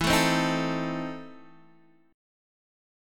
E Major 7th Suspended 4th Sharp 5th